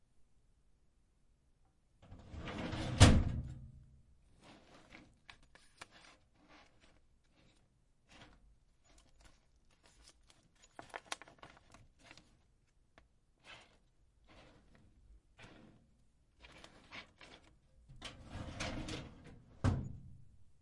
打开汽水的音效，请忽略我的前面的声音
描述：H6录制TVC拍摄时候的同期声打开可口可乐的音效
标签： H6录制 TVC 同期
声道单声道